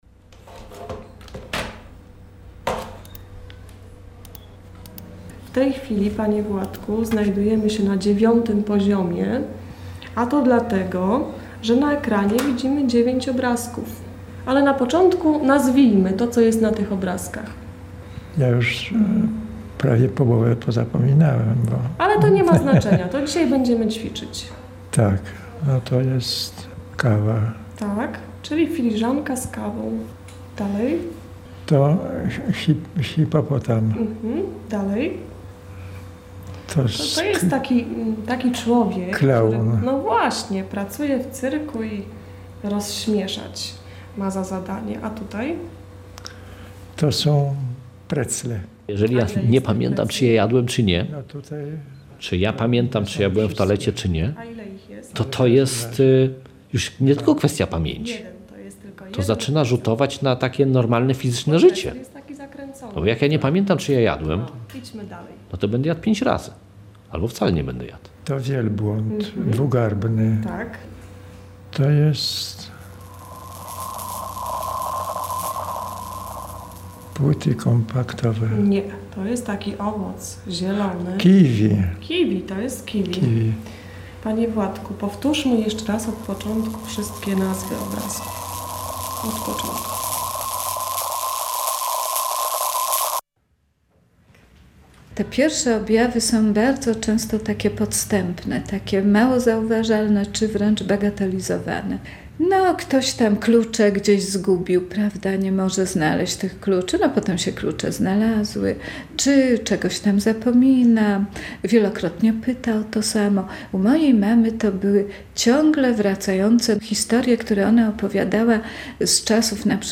Na te pytania próbują odpowiedzieć bohaterowie reportażu – opiekunowie osób cierpiących na chorobę Alzheimera.